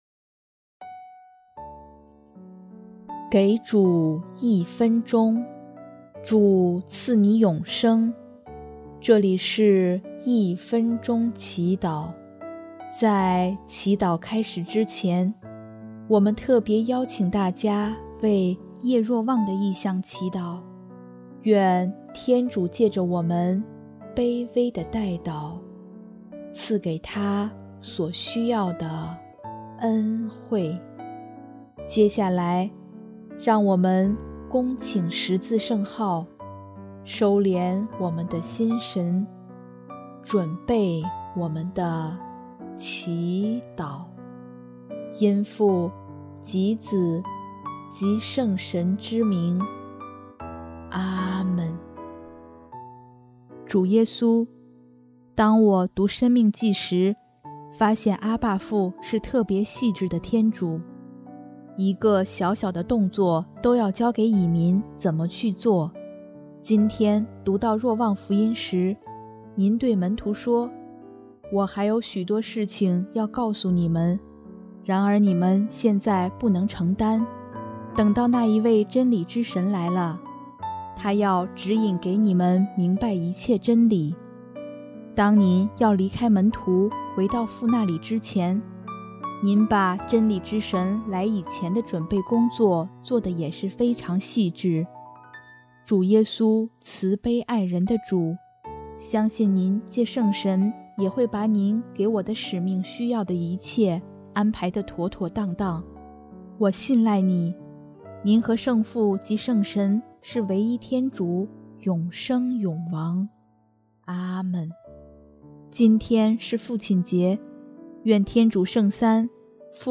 【一分钟祈祷】|6月15日 共融的天主圣三